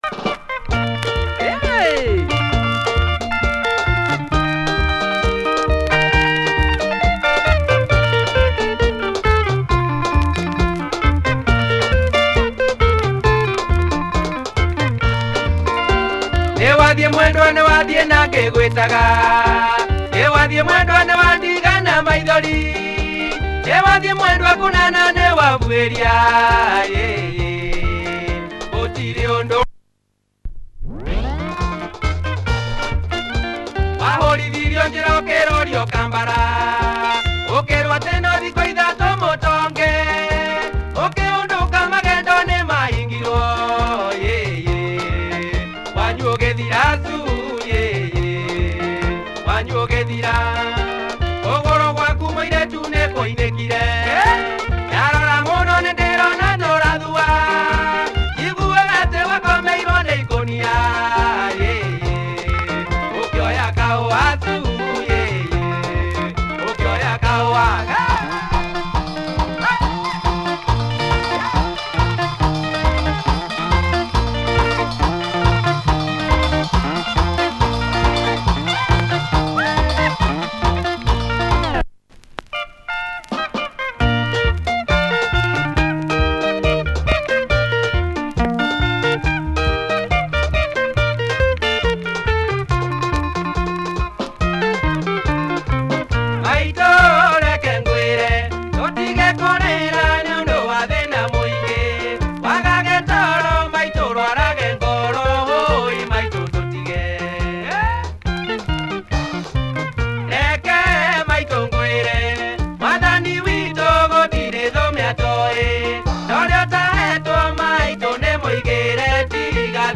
Nice benga from this famous Kikuyu outfit.